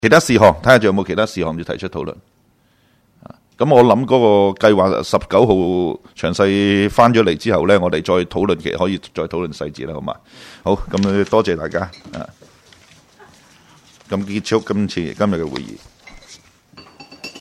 工作小组会议的录音记录
关注油麻地果栏工作小组第五次会议 日期: 2018-04-04 (星期三) 时间: 上午10时正 地点: 九龙旺角联运街30号 旺角政府合署4楼 油尖旺区议会会议室 议程 讨论时间 1.